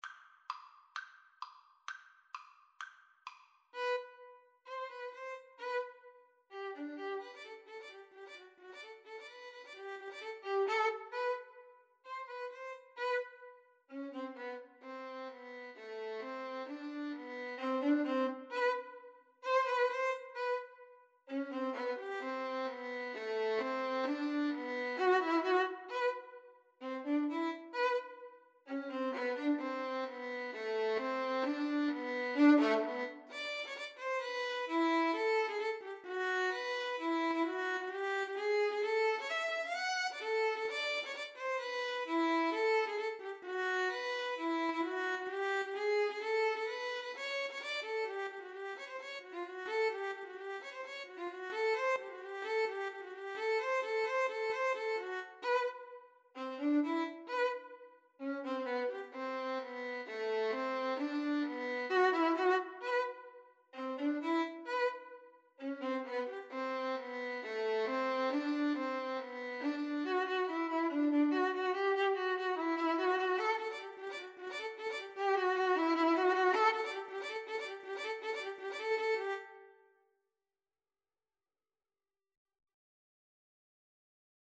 Free Sheet music for Violin Duet
Violin 1Violin 2
G major (Sounding Pitch) (View more G major Music for Violin Duet )
= 130 Tempo di trepak, molto vivace ( = c. 168)
2/4 (View more 2/4 Music)
Classical (View more Classical Violin Duet Music)